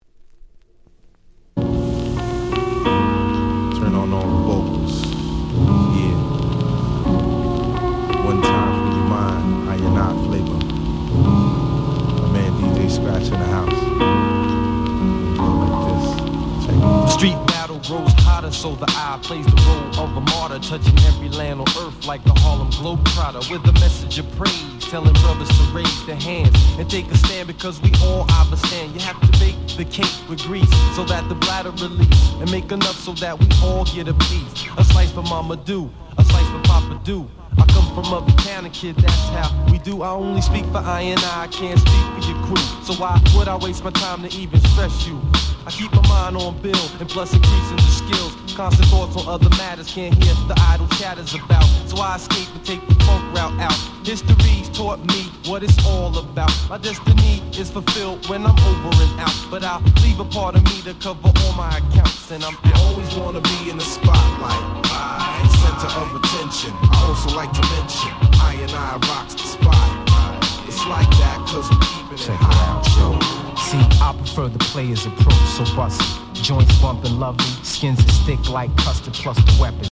ピートロック Boom Bap ブーンバップ